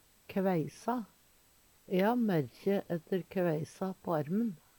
DIALEKTORD PÅ NORMERT NORSK kveisa koppevaksine Eksempel på bruk E ha merkje ætte kveisa på armen.